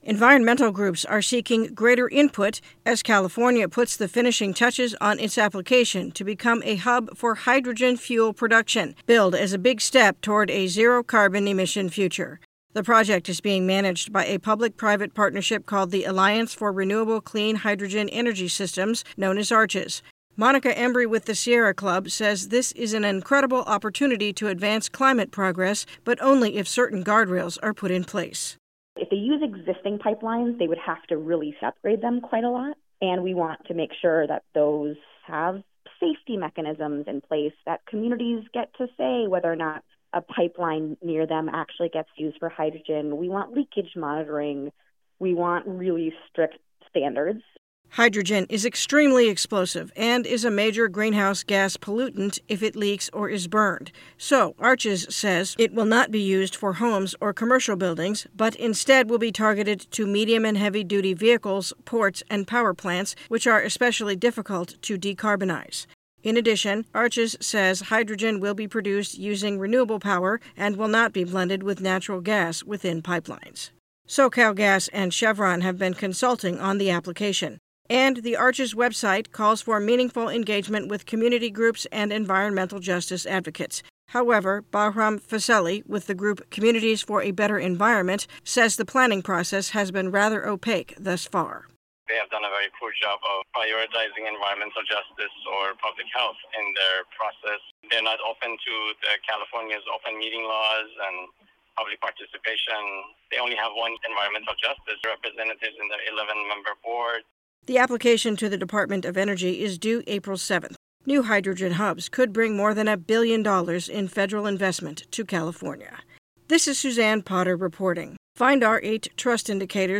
Voiceovers